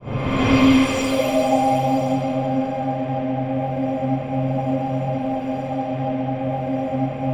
ATMOPAD24 -LR.wav